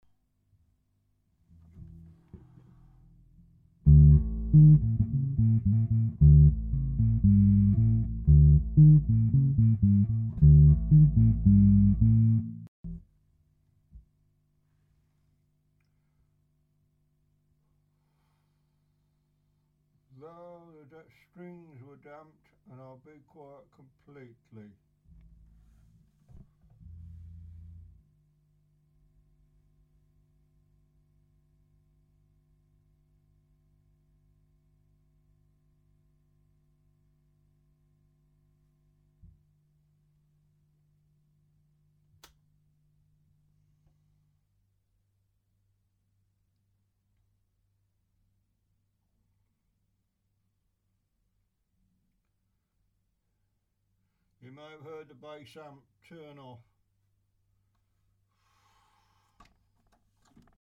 Well here's a new recording using the Samson direct box. The hum noise is better, but not gone. It seems like there's a lot more hiss noise than before, but maybe my imagination.
For this recording, no, I'm in channel 2 with XLR from the direct box. 0-8 seconds, hands on strings 8-17 seconds, hands off strings 18-41 seconds, playing with track 41-54 seconds, playing without track Attachments with direct box.mp3 with direct box.mp3 2.1 MB